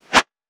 weapon_bullet_flyby_01.wav